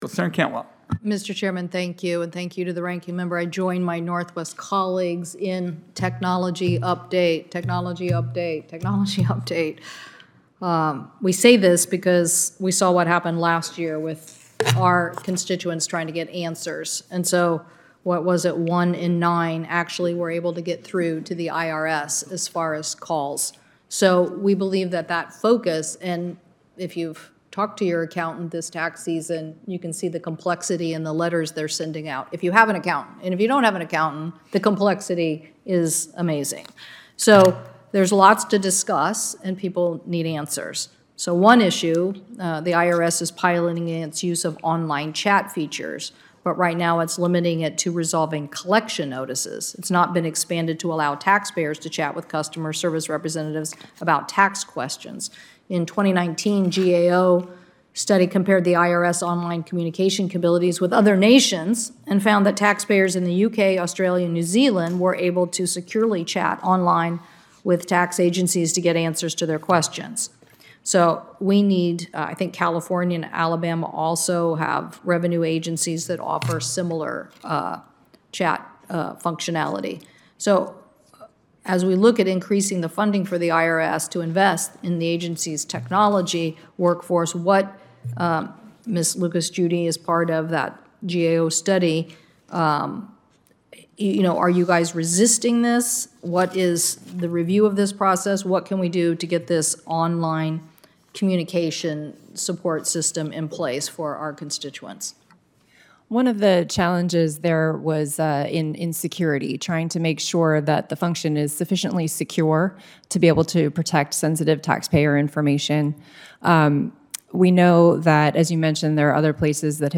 finance-hearing-on-irs-customer-service-issues-audio&download=1